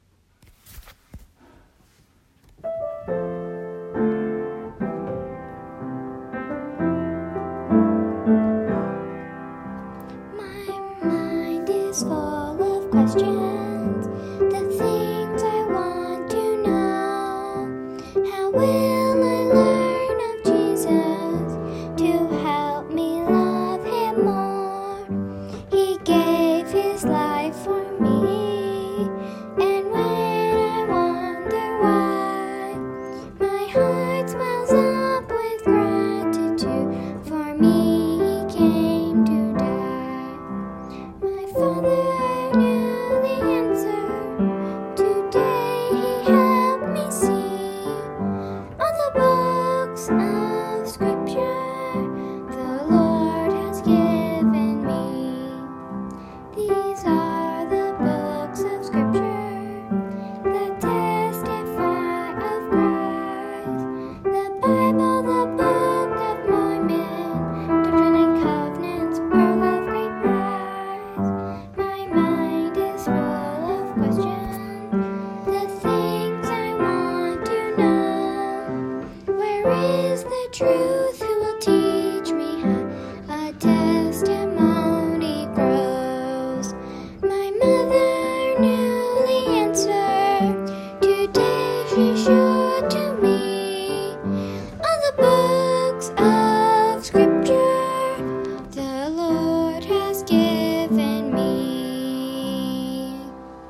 Voicing/Instrumentation: Primary Children/Primary Solo